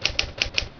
Typewriter 5 Sound Effect Free Download
Typewriter 5